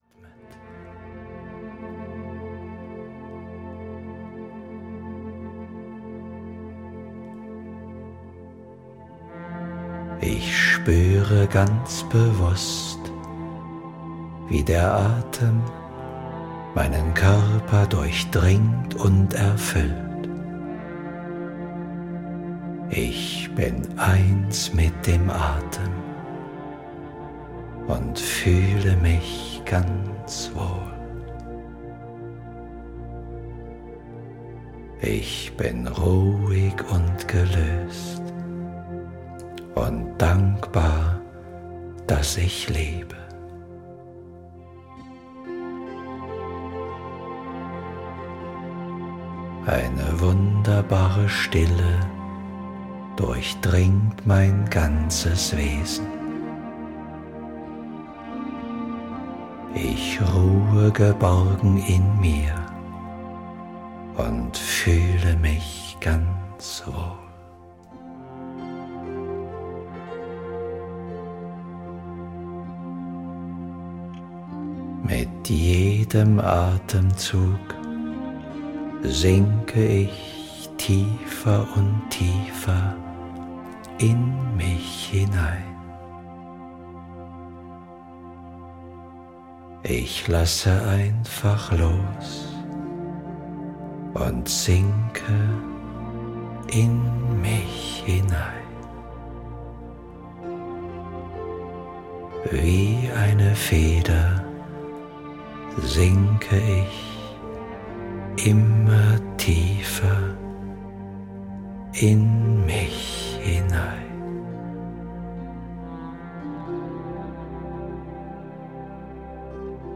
Mental Flow: Selbstmotivation - Kurt Tepperwein - Hörbuch